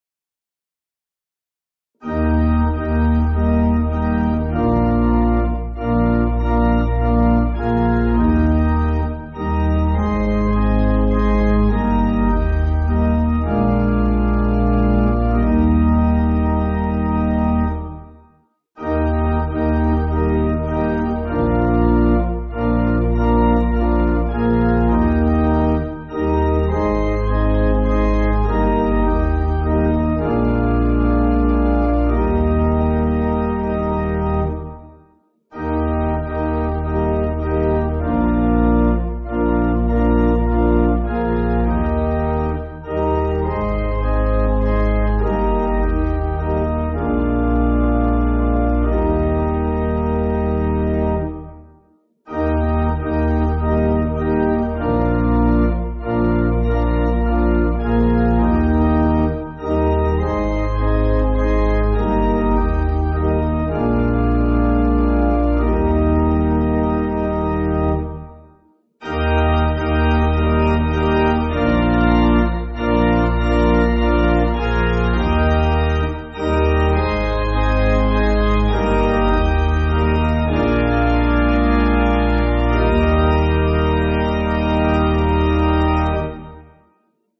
Organ
(CM)   5/Eb